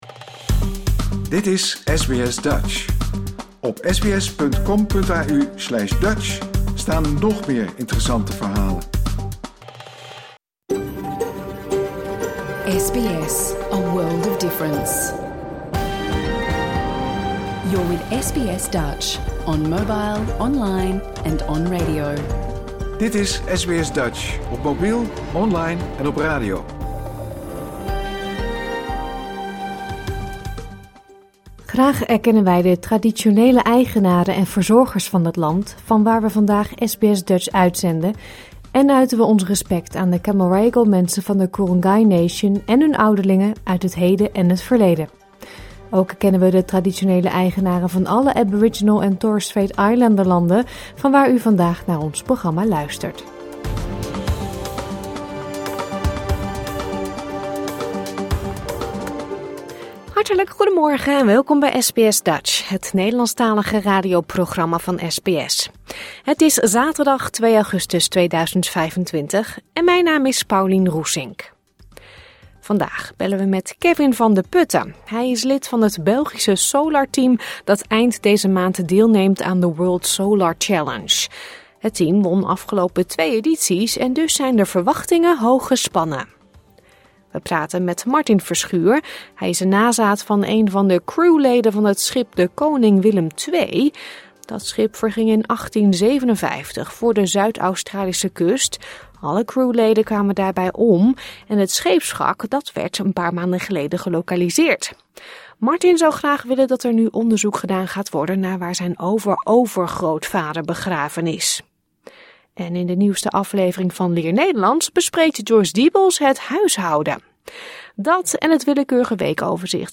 SBS Dutch gemist? Luister hier de uitzending van zaterdag 2 augustus 2025 (bijna) integraal terug.